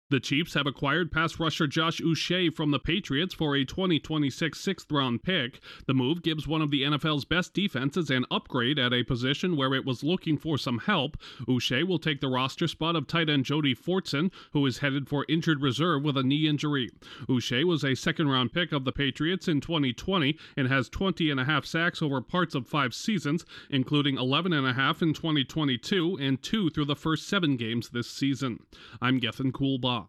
The two-time defending Super Bowl champions are making a move to bulk up their defense. Correspondent